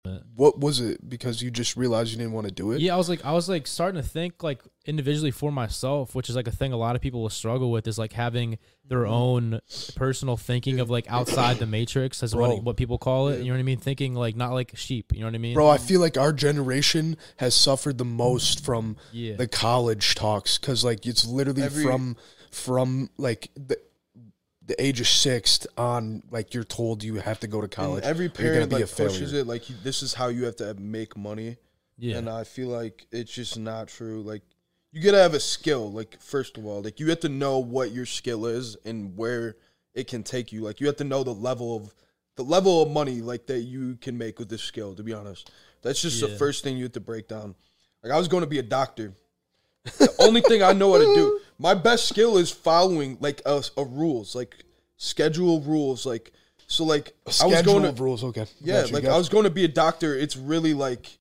With a raw perspective on the pressures of pursuing college as the optimal route to success, the speaker shares insights on recognizing individual strengths and skills that can pave the way for a fulfilling career. The conversation highlights the misconception that college is the only pathway to financial stability and encourages listeners to rethink their own journeys.